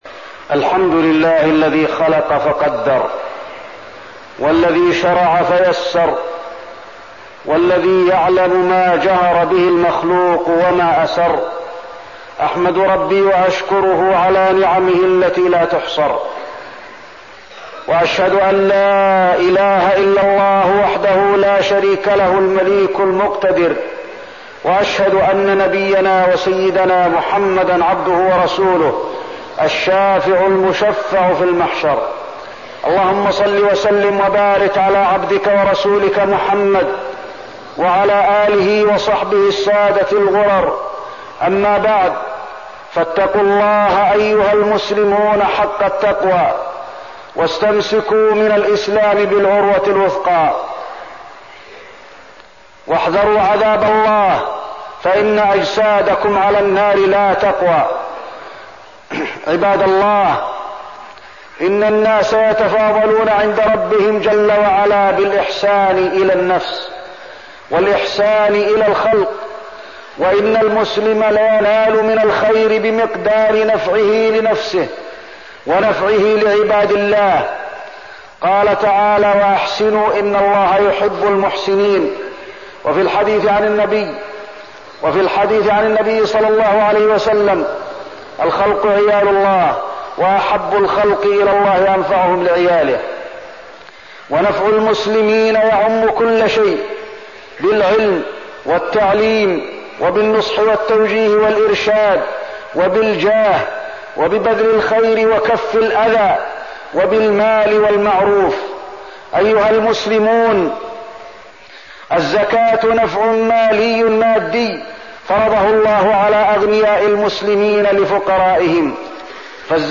تاريخ النشر ١٥ رمضان ١٤١٤ هـ المكان: المسجد النبوي الشيخ: فضيلة الشيخ د. علي بن عبدالرحمن الحذيفي فضيلة الشيخ د. علي بن عبدالرحمن الحذيفي الزكاة